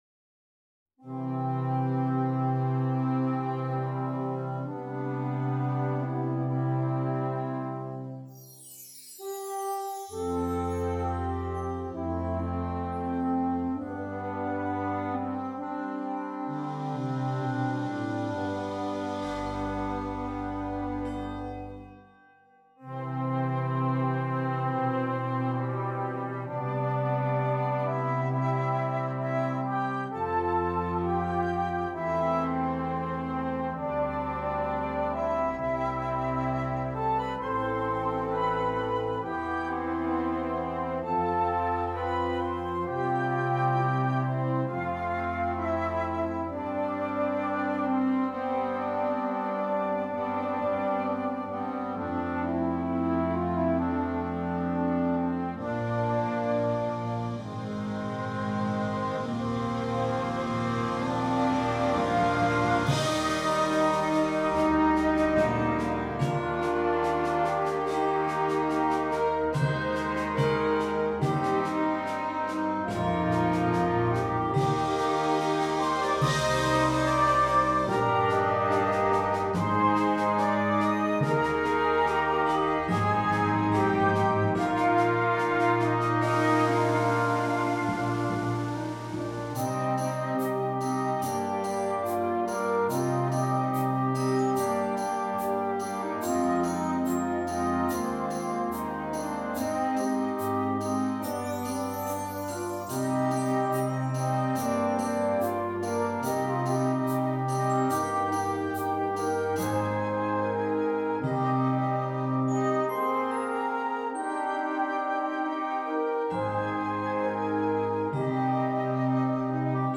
Sounding very patriotic in nature